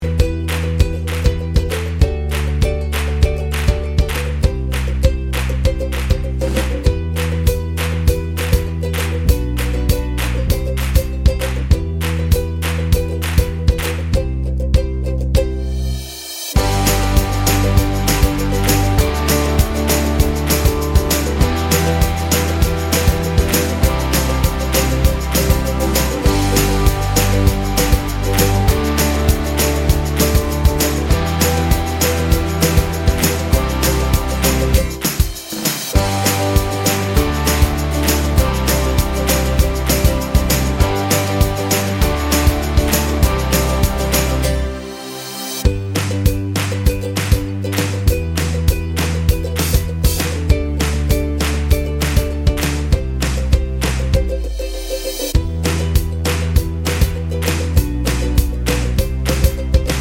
Pop (2020s)